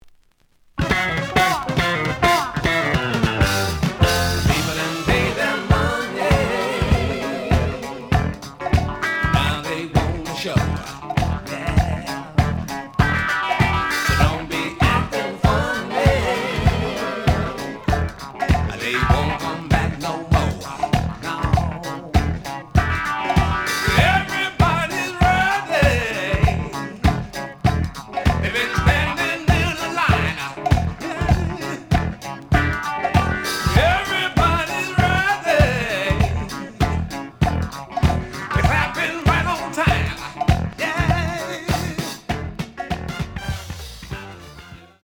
試聴は実際のレコードから録音しています。
The audio sample is recorded from the actual item.
●Genre: Soul, 80's / 90's Soul